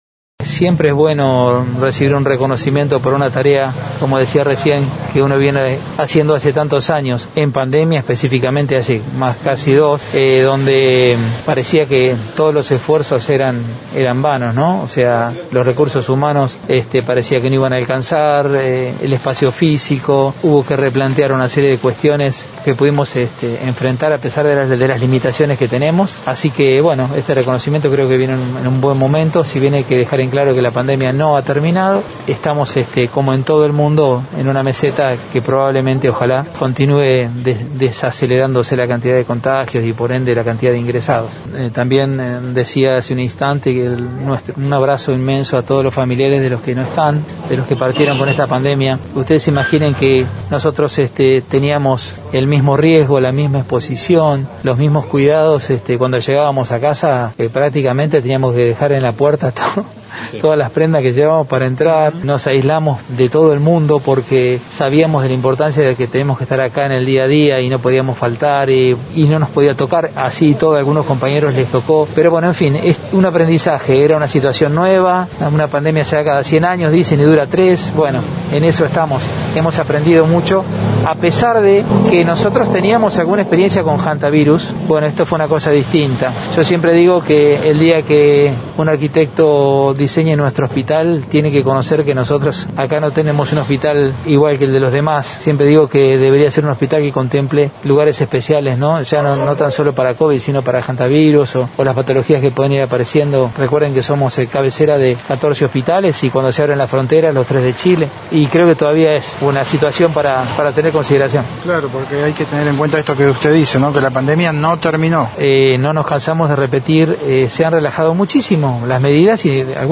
Escuchá la palabra del intendente luego de destapar la placa en el ingreso al Hospital Zonal de Esquel.